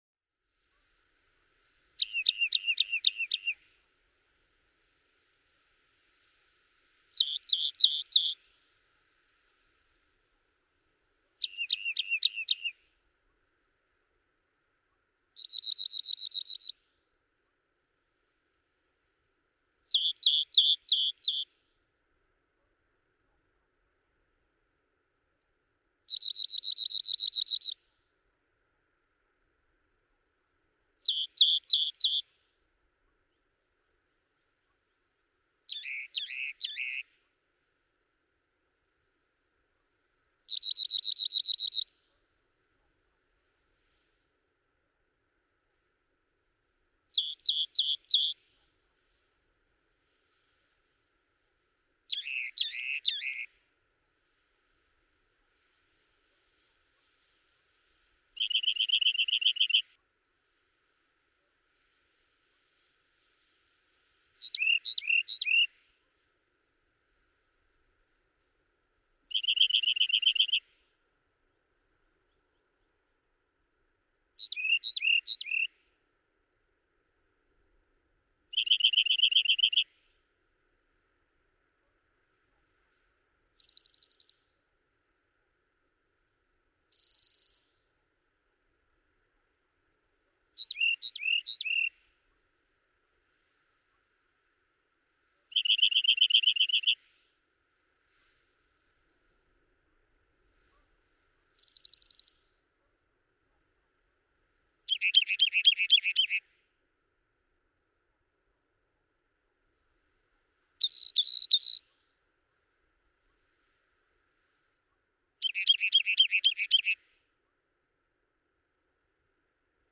Rock wren
Over 200 songs, delivered in typical rock wren fashion. Sometimes he was at the top of the boulder looking down on me, and when he slipped behind the boulder, his songs were not as loud. During his dozen songs in the first minute, he uses five different songs in the following sequence: A B A C B C B D C B D E. June 12, 2008. Jeffrey City, Wyoming.
405_Rock_Wren.mp3